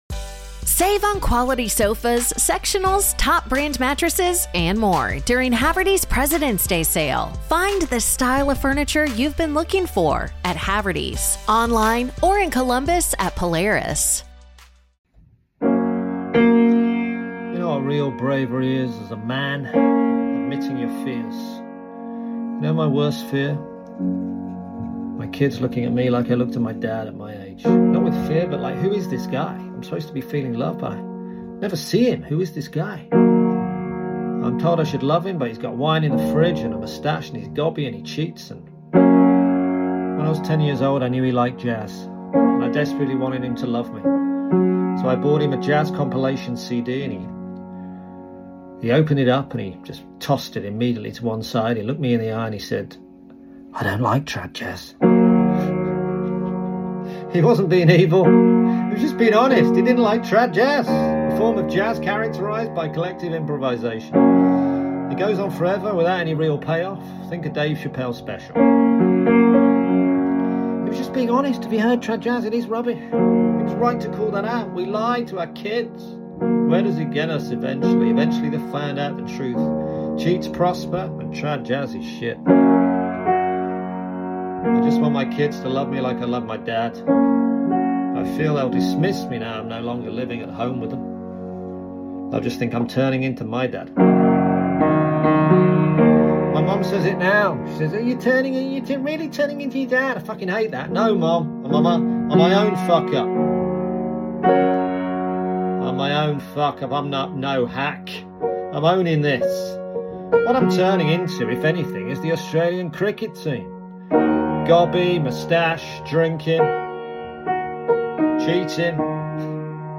Trad jazz.